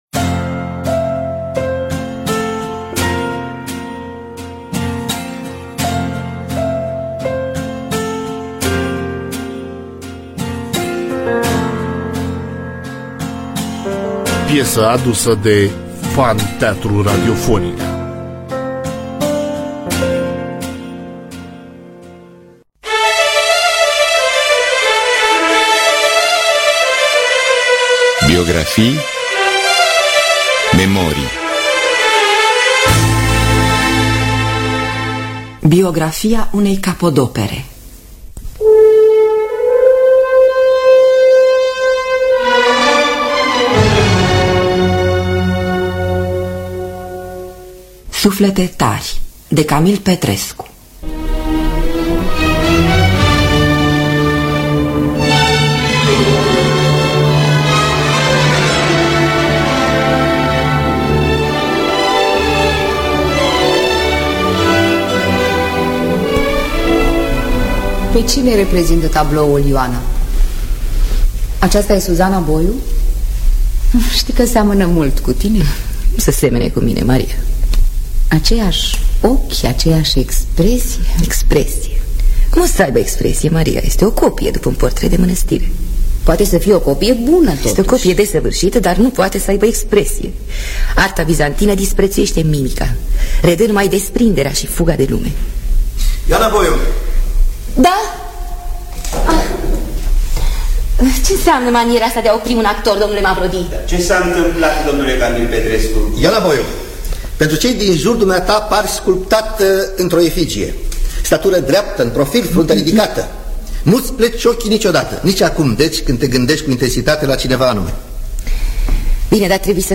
Biografii, Memorii: Camil Petrescu – Suflete Tari (1975) – Teatru Radiofonic Online